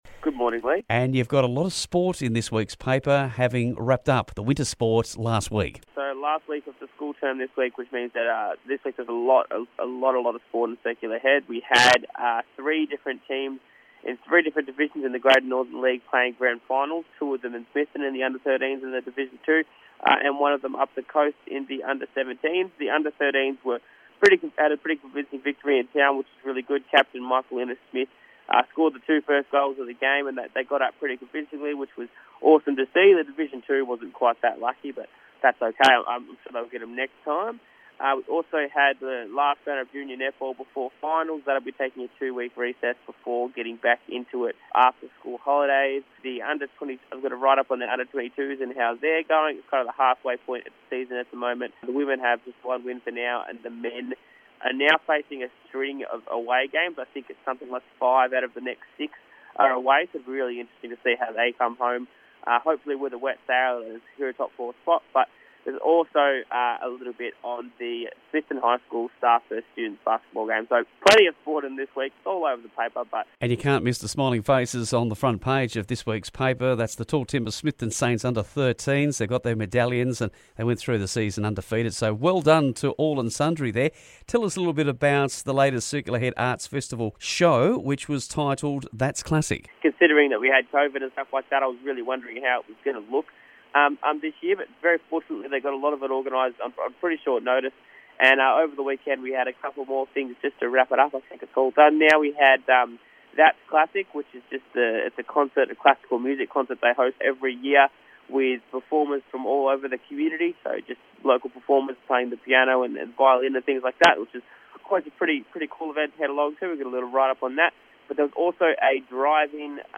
Circular Head Chronicle News Headlines-October 1